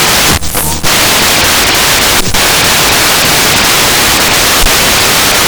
Декодируется в shipplotter - 7 корабликов